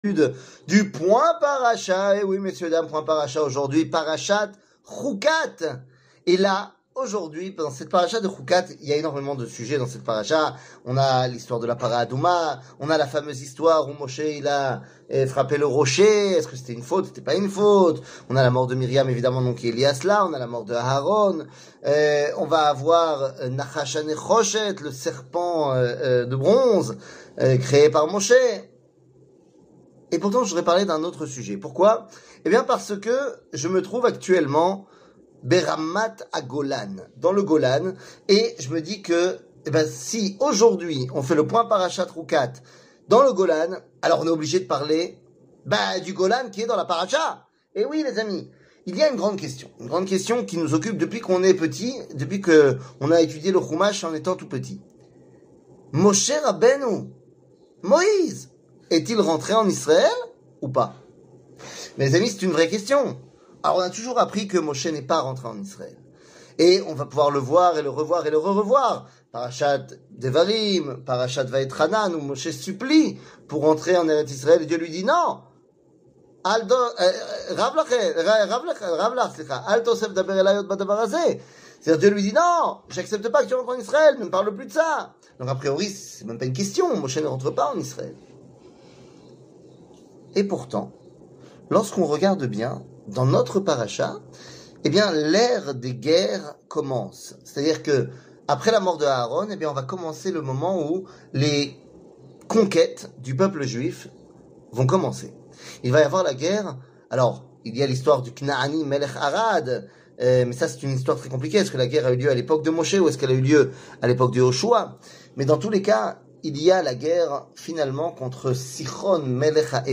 שיעור מ 22 יוני 2023 07MIN הורדה בקובץ אודיו MP3 (7.05 Mo) הורדה בקובץ וידאו MP4 (13.61 Mo) TAGS : שיעורים קצרים